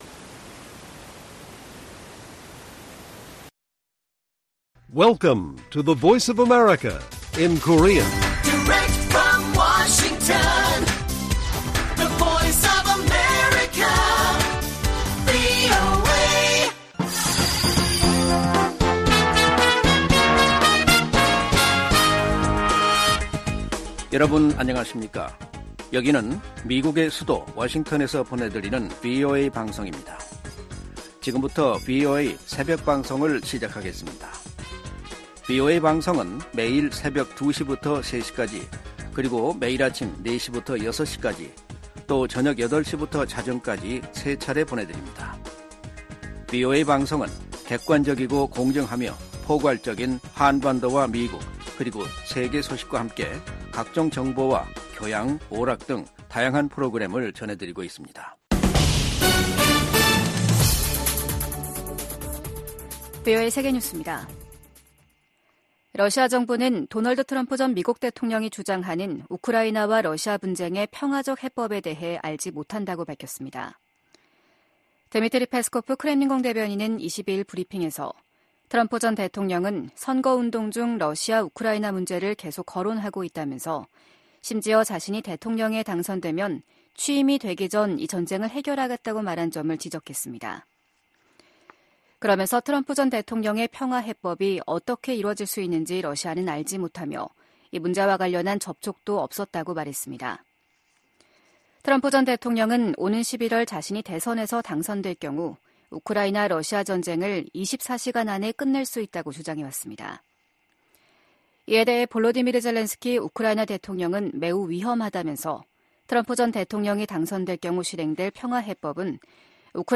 VOA 한국어 '출발 뉴스 쇼', 2024년 1월 23일 방송입니다. 미 국무부가 북한의 수중 핵무기 시험 주장에 도발을 중단하고 대화에 나서라고 촉구했습니다. 백악관은 수중 핵무기 시험 주장과 관련해 북한이 첨단 군사 능력을 계속 추구하고 있음을 보여준다고 지적했습니다.